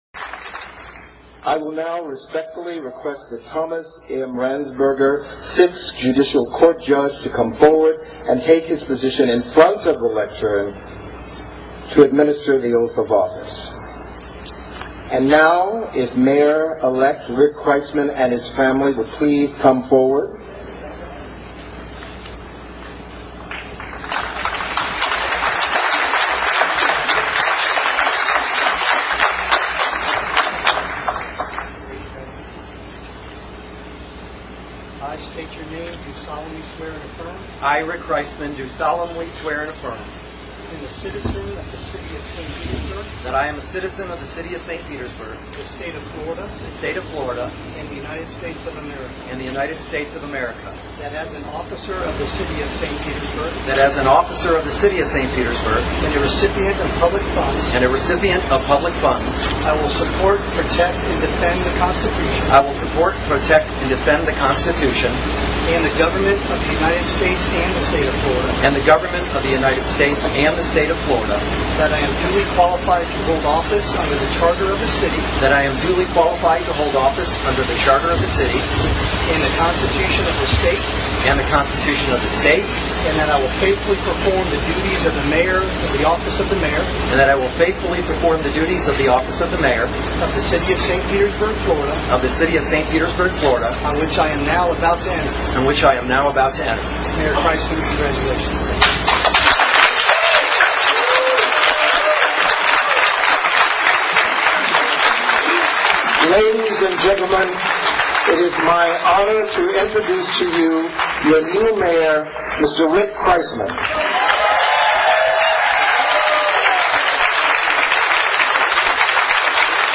Oath of Office and Remarks -Mayor Rick Kriseman 1-2-14 City Hall